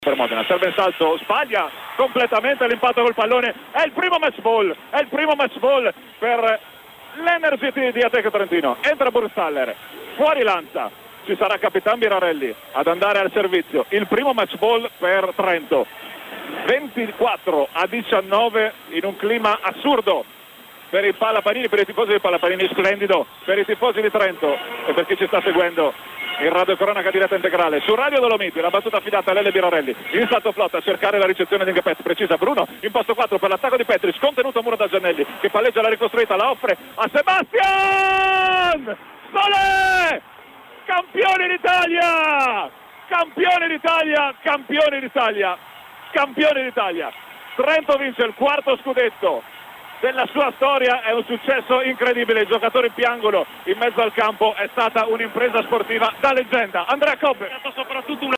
Radiocronache ultimi punti